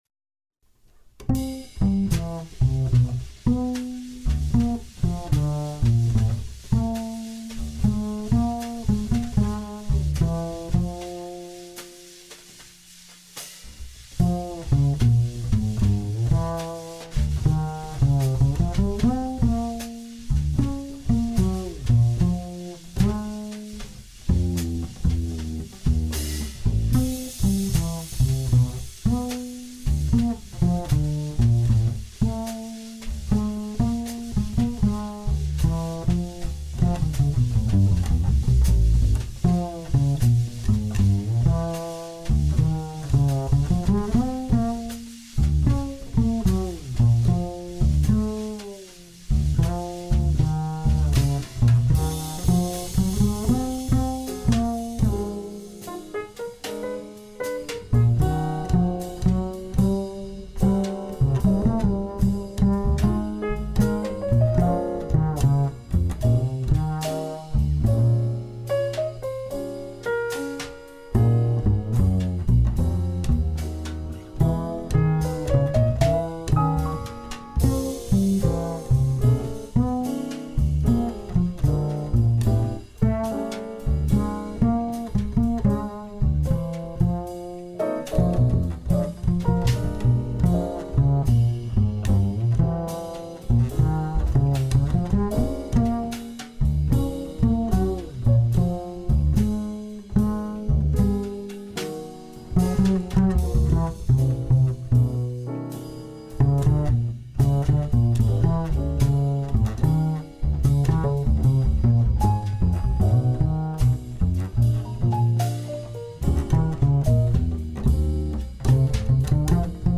あくまでも優しく、癒しを追及した
極上のJazzサウンド!!
Piano
Bass
Drums